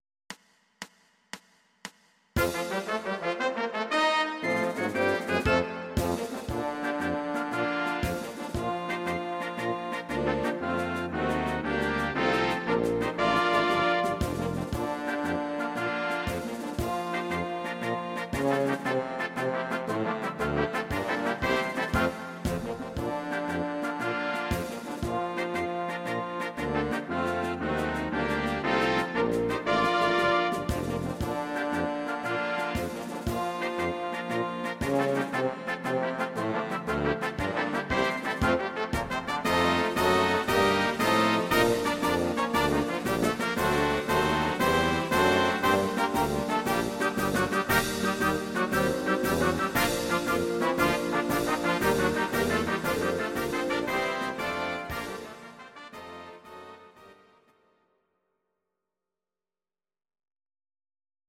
Blasmusik